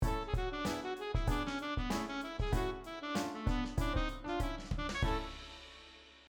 Obviously drawing the scale going upwards may tend towards a result that mostly goes upwards (and then maybe back down again), so here instead the scale was drawn downwards and then fiddled with, which resulted in a mostly down and then back up line, though the overall arc is masked by smaller note groups, and there's a piano and drum set for who knows what reasons.
The name is on account of the key A Major being used, except when it is not; perhaps someone more clever at harmony can figure out what is going on (having a harmonic plan and a larger scale structure might yield larger scale works).